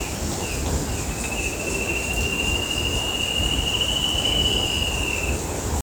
Spot-backed Antshrike (Hypoedaleus guttatus)
Location or protected area: Reserva Privada San Sebastián de la Selva
Condition: Wild
Certainty: Observed, Recorded vocal